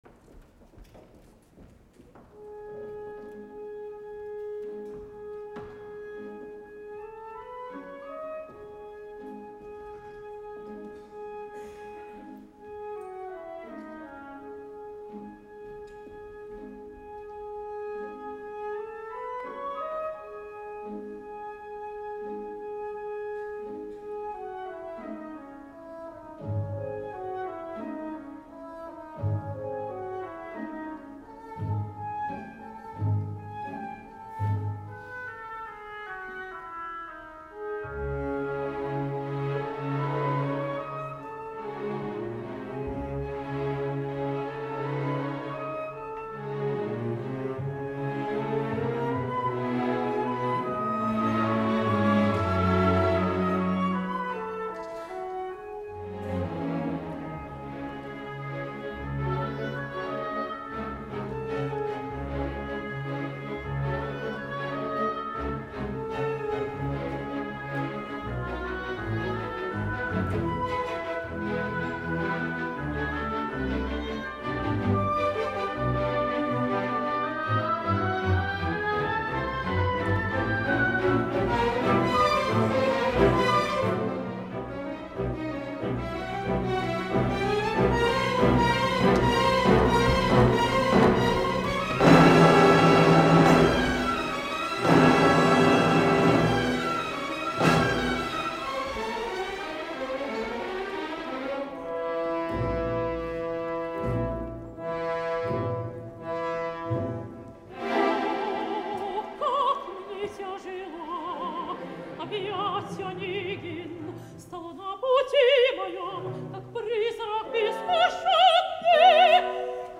Krassimira Stoyanova (Tatiana) a la DNO producció Stefan Herheim
Abans d’ahir tenia lloc la quarta representació de les deu previstes a la De Nederlandse Opera d’Amsterdam de l’òpera Piotr Ilitx Txaikovski, Ievgeni Onegin, dins del marc del Festival d’Holanda.
La representació comptava amb la meravellosa direcció del director de l’Orquestra del Concertgebouw Mariss Jansons, que allunyant-se de tot sentimentalisme, atorga a l’orquestra una paleta immensa de matisos dramàtics i de sonoritats obscures, amb una esplendorosa resposta de la seva magnificent orquestra, que subratlla totes i cadascuna de les emocions que s’apleguen en aquesta òpera, amb una qualitat sonora i intensitat dramàtica, absolutament envejable.
Uns instruments solistes de somni i una conjunció orquestral d’una qualitat estratosfèrica, en especial les fustes i les cordes que ja veureu com llueixen.
duo-final.mp3